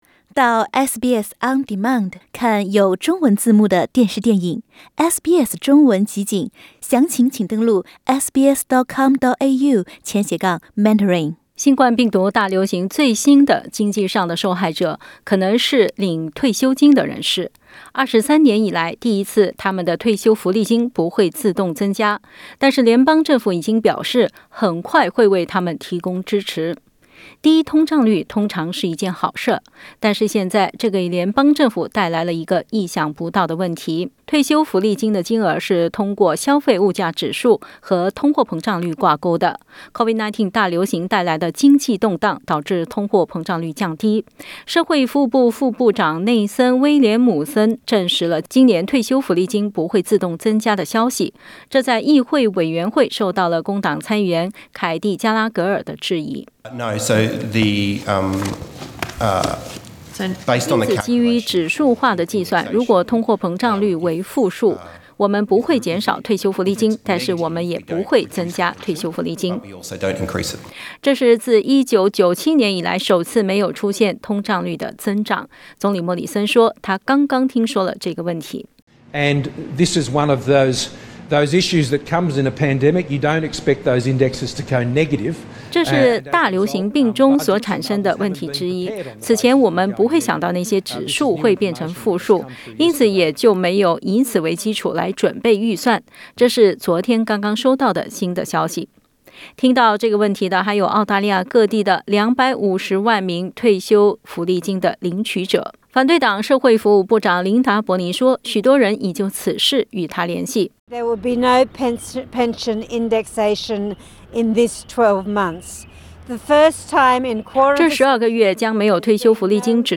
23年以来第一次他们的养老金不会自动增加。但是联邦政府已经表示，很快会为他们提供支持。 点击图片收听详细报道。